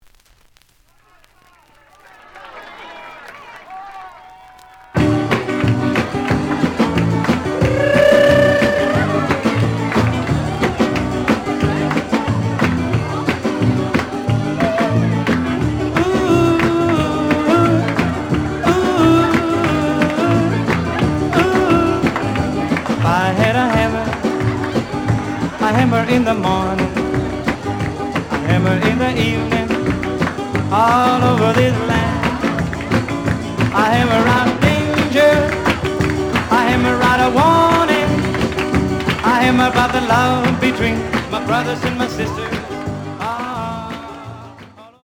The audio sample is recorded from the actual item.
●Genre: Rhythm And Blues / Rock 'n' Roll
Edge warp. But doesn't affect playing. Plays good.)